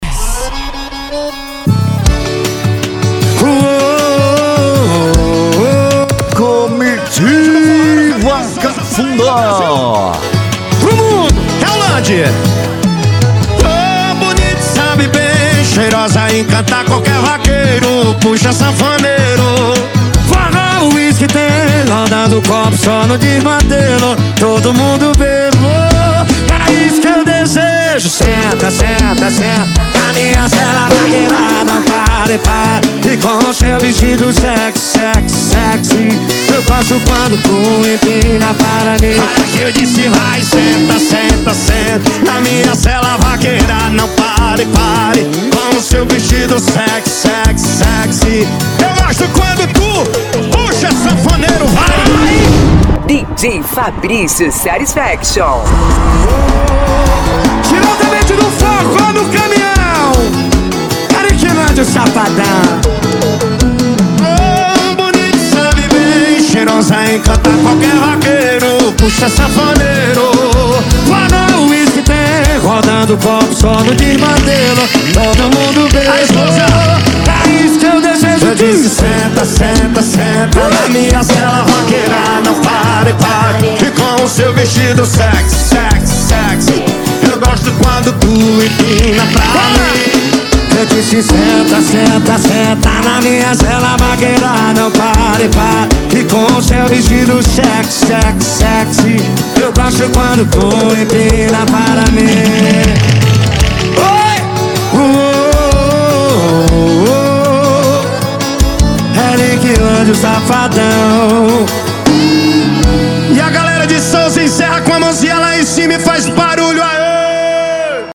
Arrocha
Funk
SERTANEJO
Sertanejo Universitario
Sets Mixados